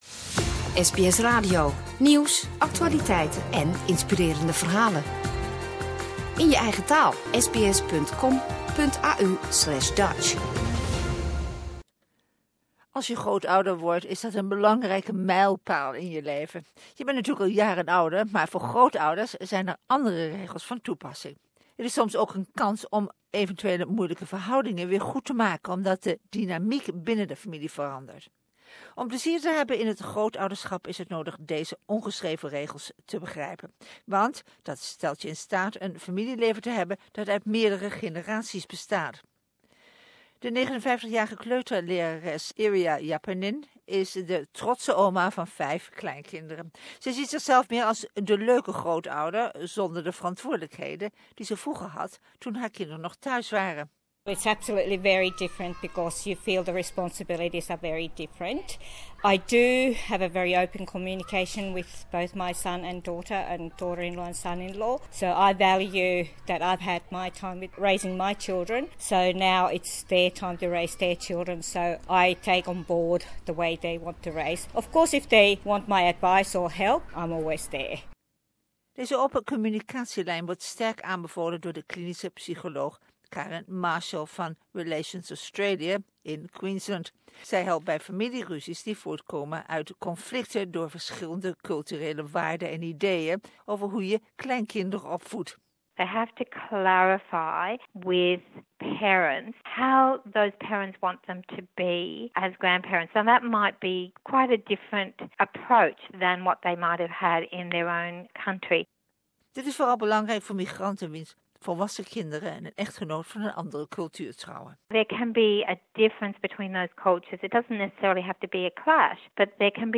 For those who have taken years to learn how to parent, becoming a grandparent requires a completely different set of rules, and often, the ability to readjust as the family dynamics change. In this Viva episode we talk to grandparents, a social researcher and a clinical psychologist from Relationship Australia.